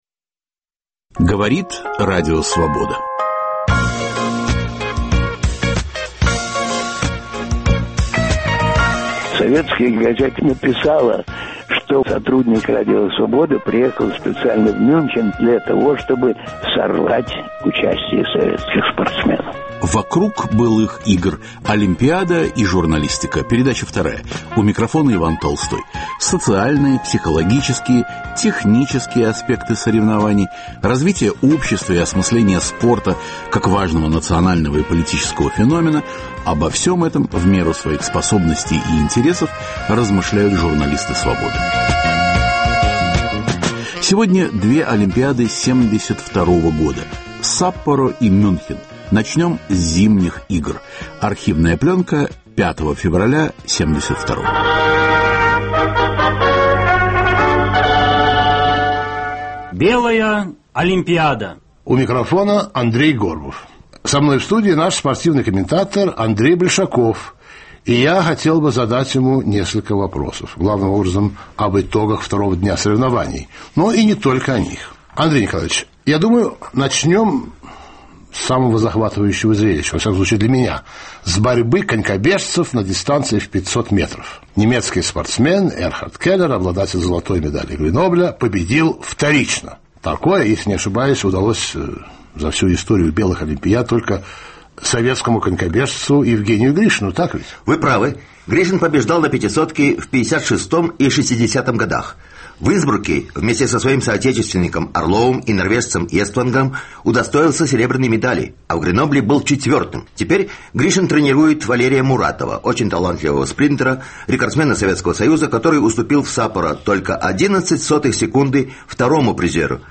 В очередном выпуске - разговор о Саппоро-72 и трагических Мюнхенских играх. Звучат старые записи из архива Свободы.